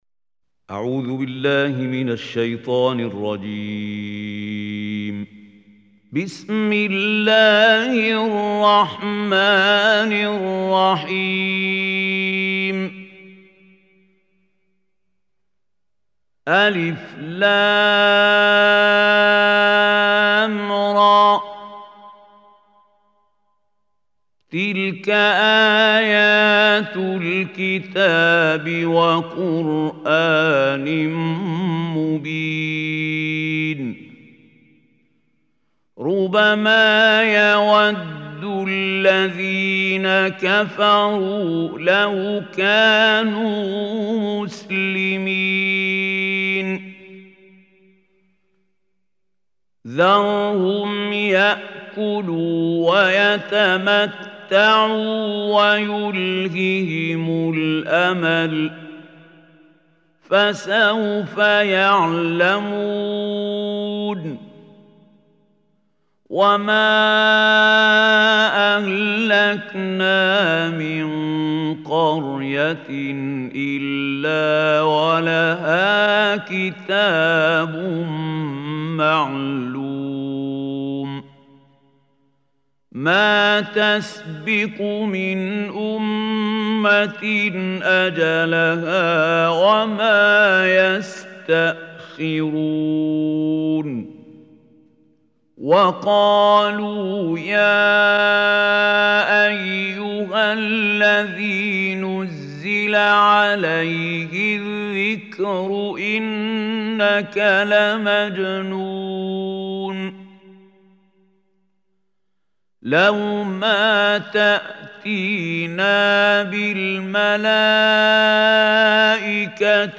Surah Al Hijr Online Recitation by Khalil Hussary
Surah Al Hijr is 15th Chapter of Holy Quran. Download Surah Hijr tilawat mp3 in the voice of Qari Mahmoud Khalil Al Hussary.